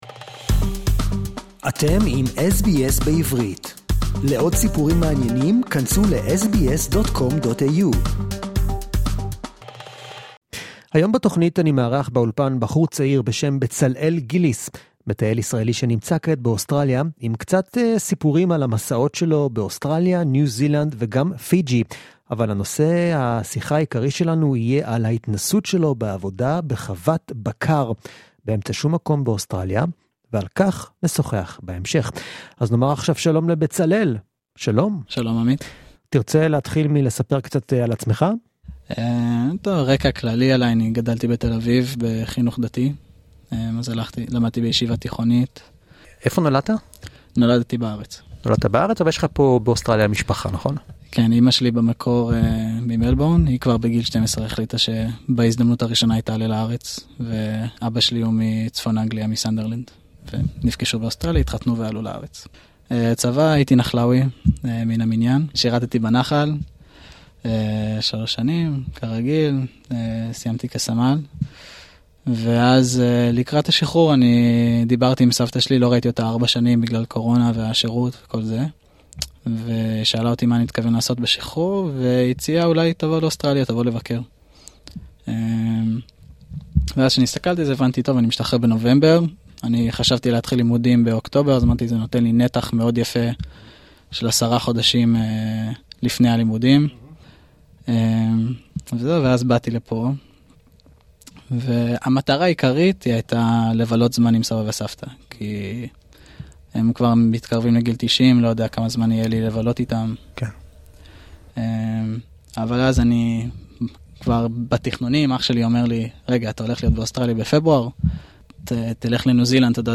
We foresee that there will be a stampede of students from Jewish schools such as Scopus, Bialik, Yavne, Moriah College etc applying to work in remote cattle stations across Australia after listening to this riveting interview!